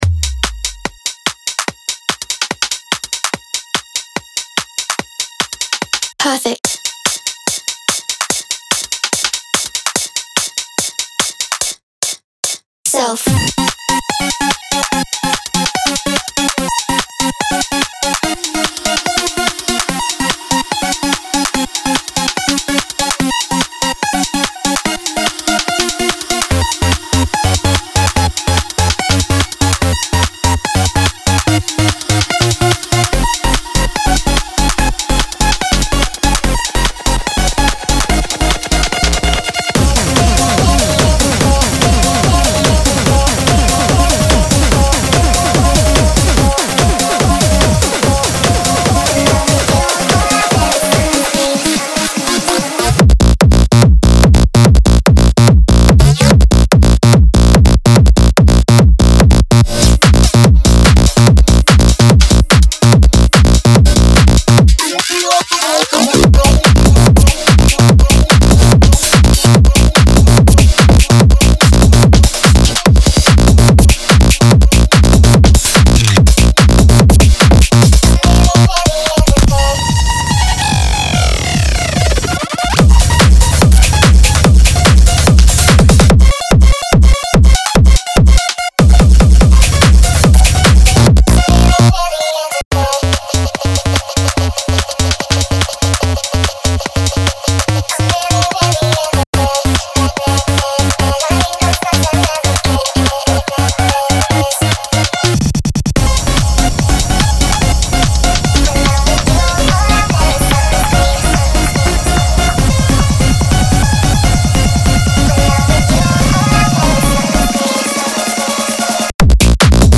BPM145-145
Audio QualityPerfect (High Quality)
Hard Dance song for StepMania, ITGmania, Project Outfox
Full Length Song (not arcade length cut)